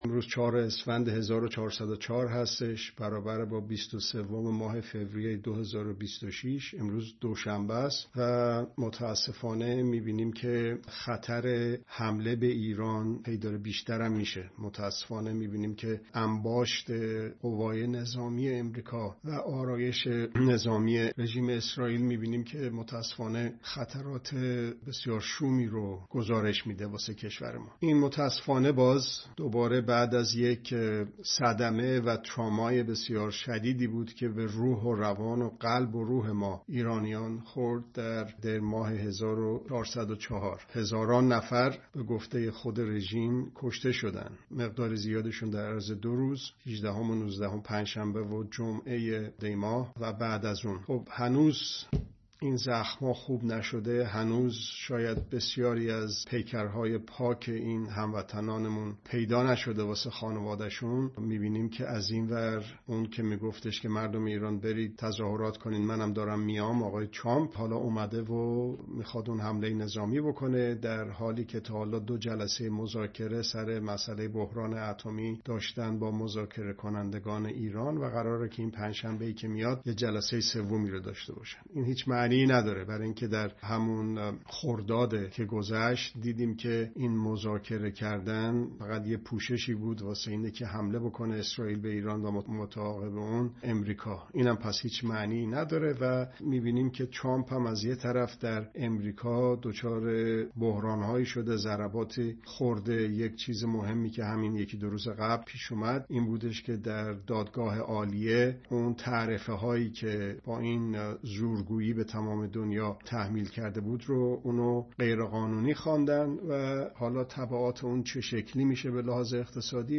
هفته‌نامه گزارش، بررسی، و ارزیابی رویدادها ❊ ( دوشنبه‌ها ساعت۲۱ به‌وقت ایران به‌صورت زنده